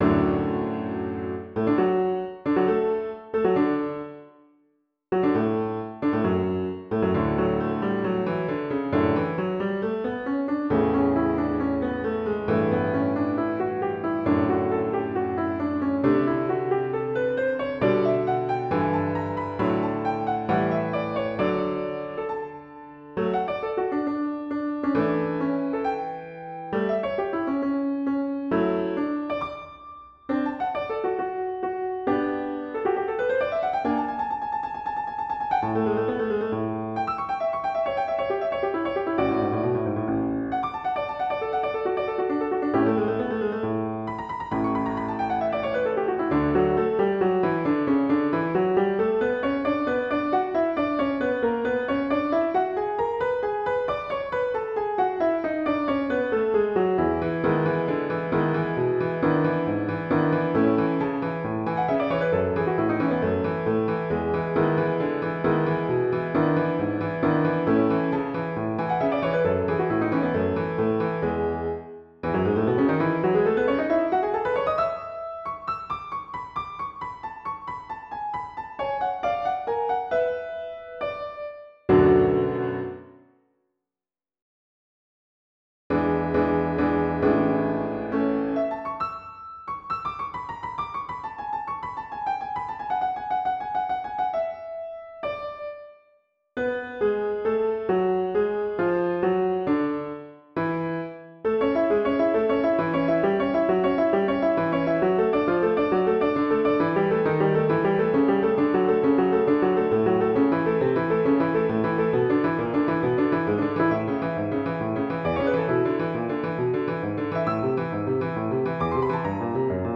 베토벤 바이올린 소나타 1번 1악장 Beethoven-violin-sonata1-1 > 바이올린 | 신나요 오케스트라